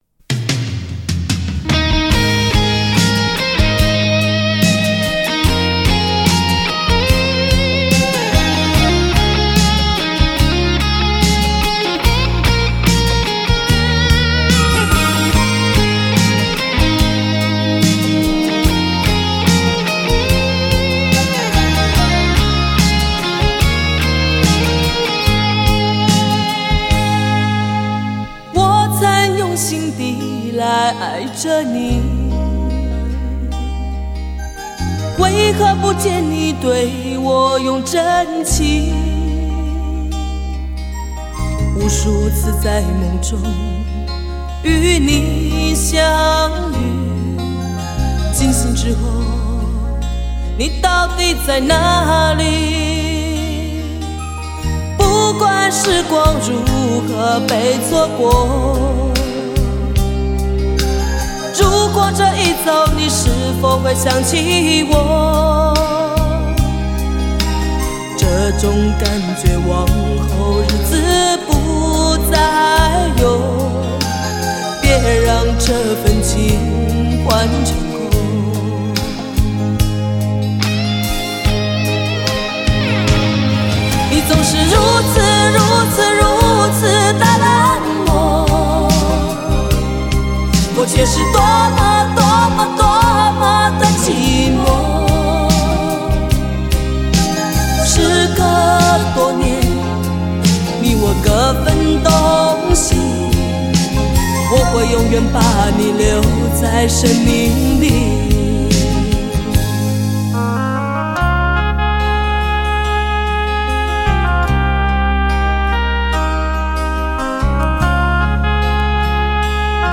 痛楚来自她一针见血的穿透力
她却唱出最有温度的声音